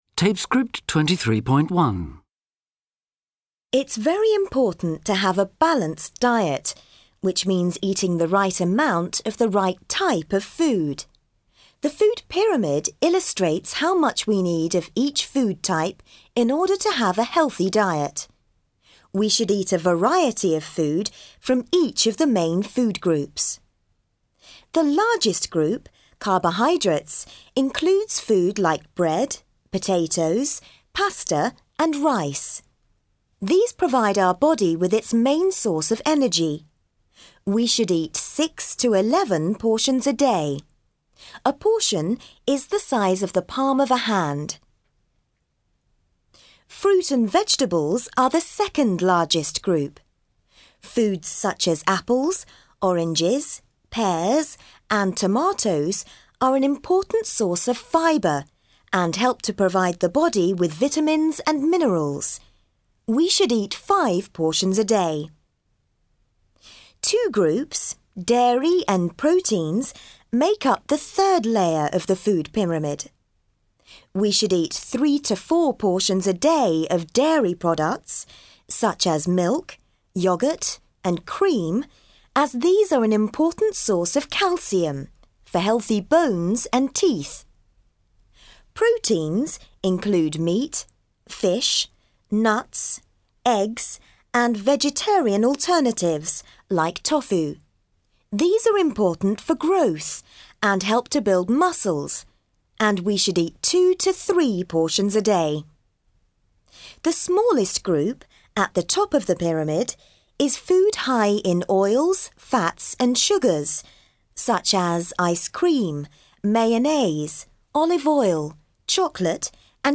5. Mokytojas pakviečia mokinius pasiklausyti trumpos dietologo paskaitos apie sveiką mitybą ir sveiko maisto piramidę. 5.